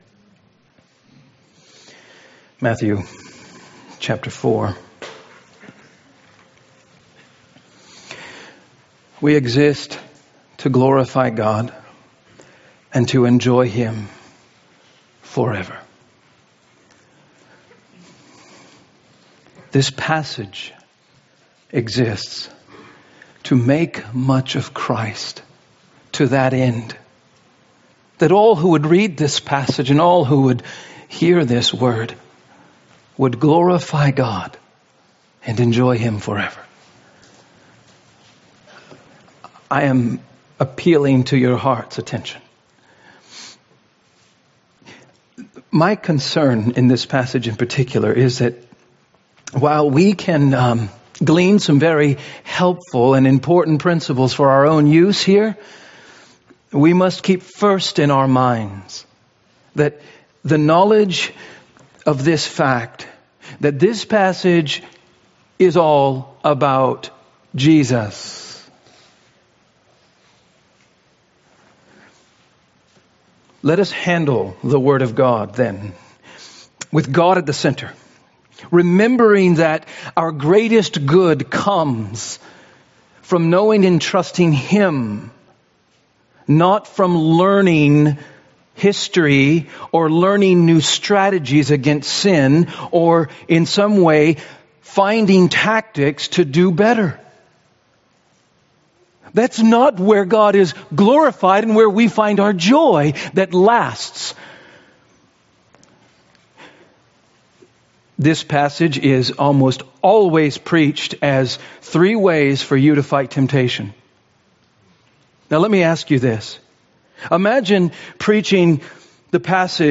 A message from the series "Christ and Kingdom."